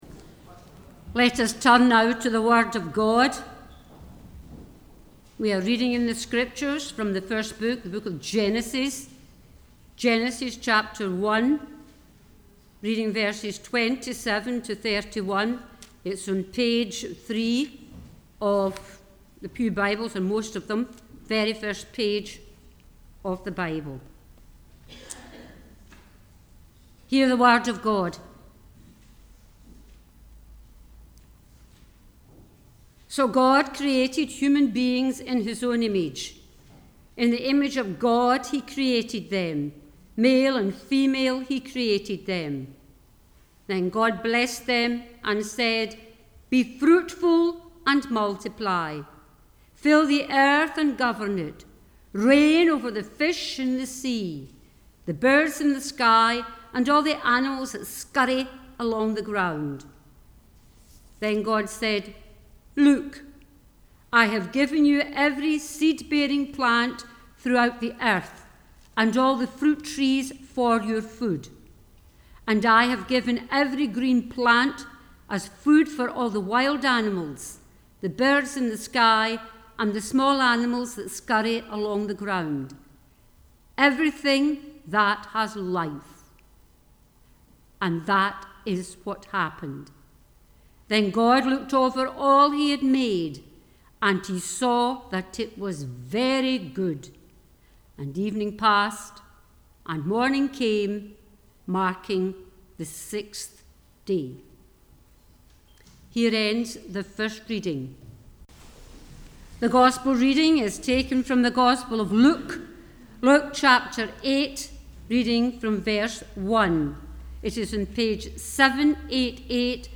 The Scripture Readings prior to the Sermon are Genesis 1:27-31 and Luke 8: 1-15